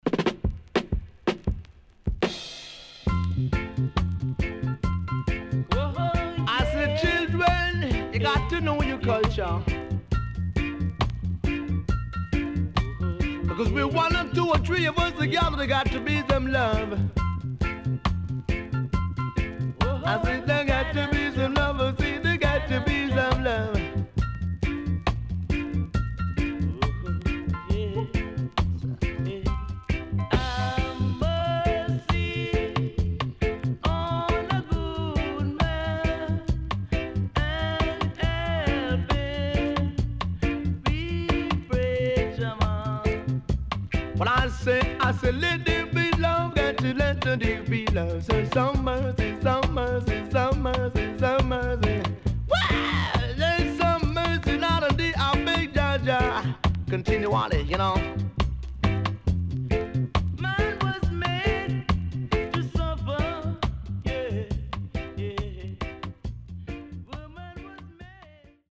HOME > LP [VINTAGE]  >  70’s DEEJAY
SIDE B:所々チリノイズがあり、少しプチノイズ入ります。